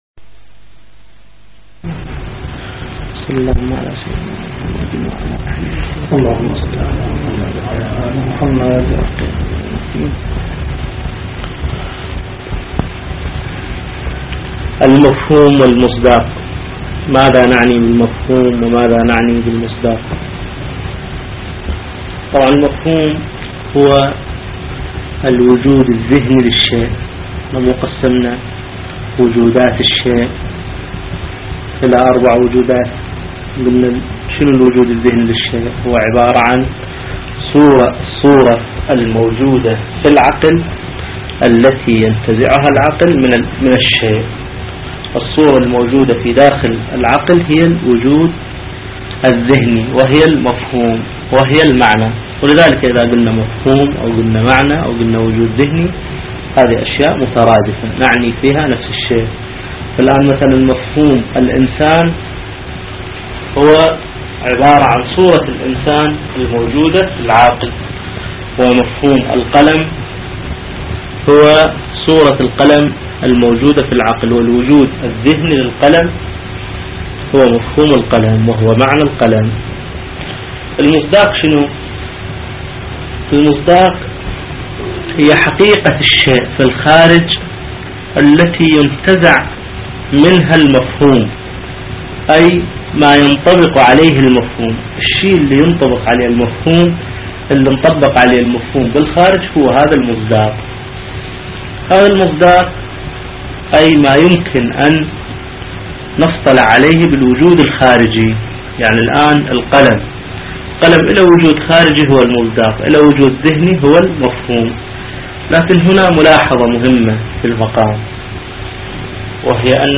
صوت الدرس